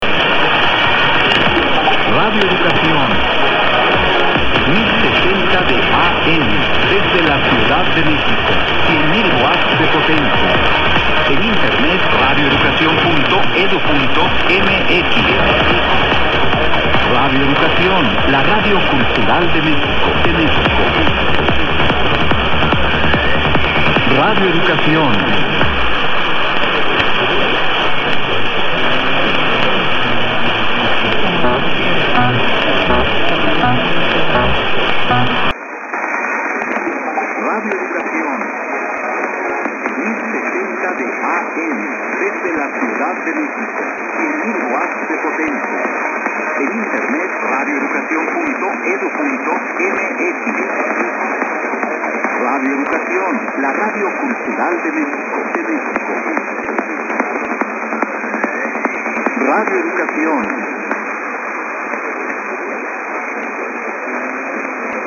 To my ears, the compression rate on the HDSDR audio is so poor as to make the audio whine almost.
130918_0532_1000_komo_perseus_then_hdsdr.mp3